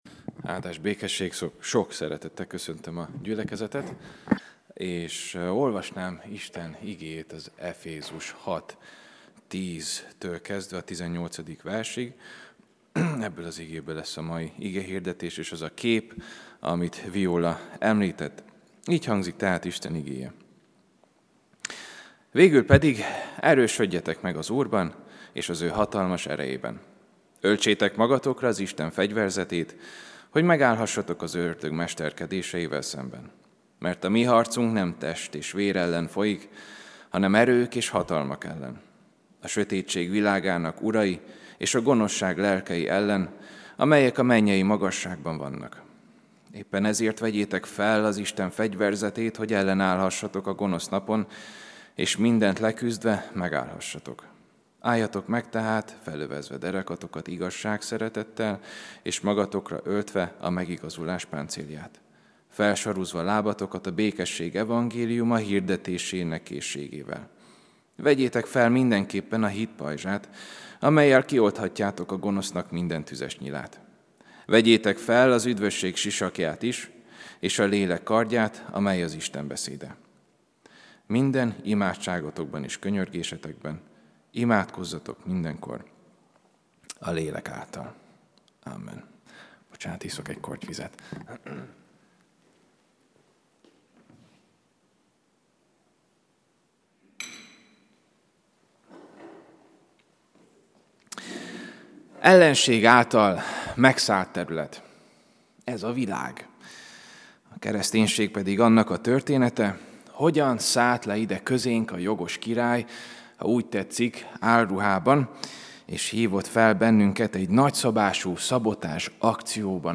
AZ IGEHIRDETÉS LETÖLTÉSE PDF FÁJLKÉNT AZ IGEHIRDETÉS MEGHALLGATÁSA